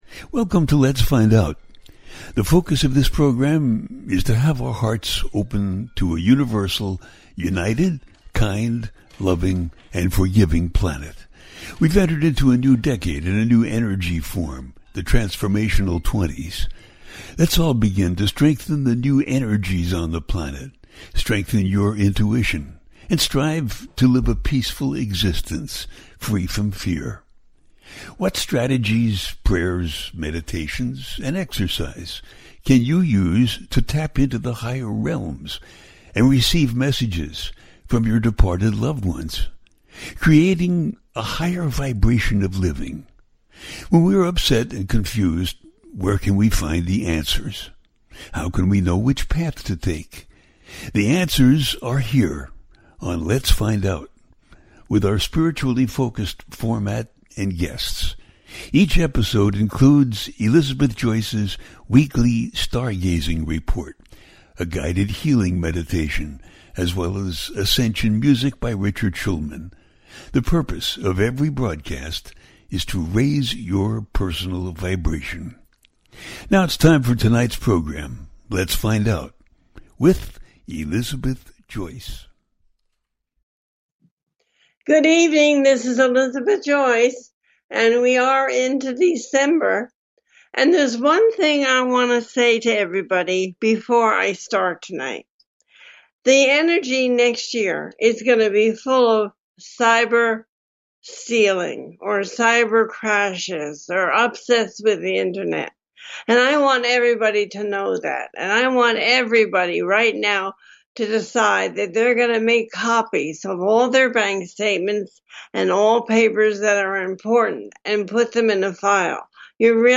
The listener can call in to ask a question on the air.
Each show ends with a guided meditation.